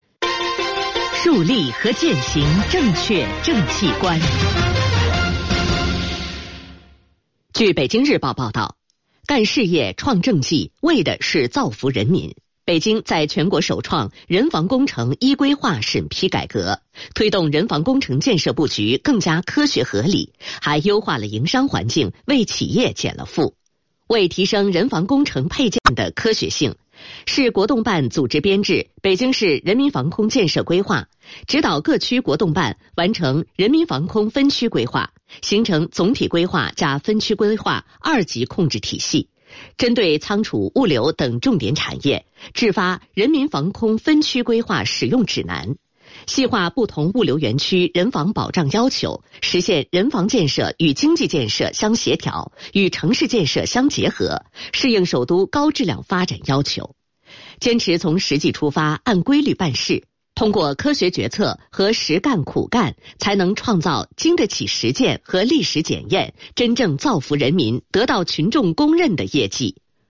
北京新闻广播报道： 北京新闻台广播-市国动办全国首创人防工程“依规划”审批改革，助力优化营商环境 “该建的一个不少，不该建的一个不建”.mp3 （此条为音频信息）